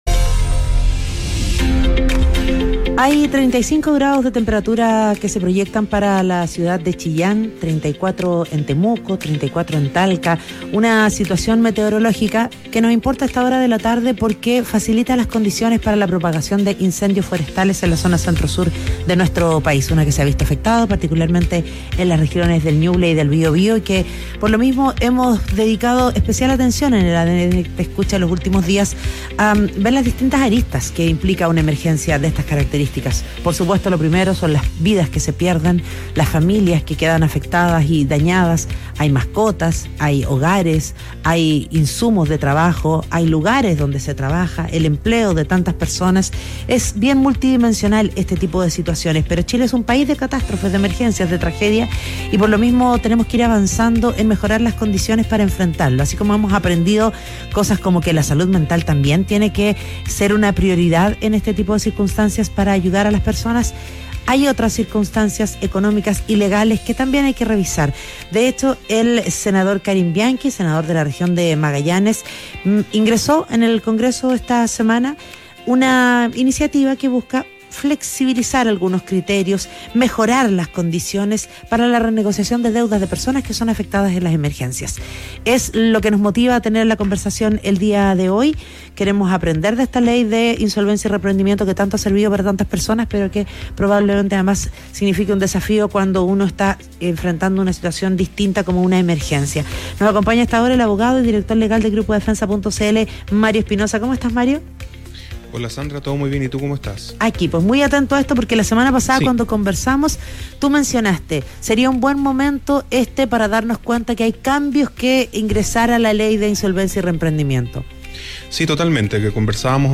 En conversación con ADN Te Escucha, el senador Karim Bianchi propone reducir plazos de morosidad y flexibilizar requisitos en contextos de emergencia.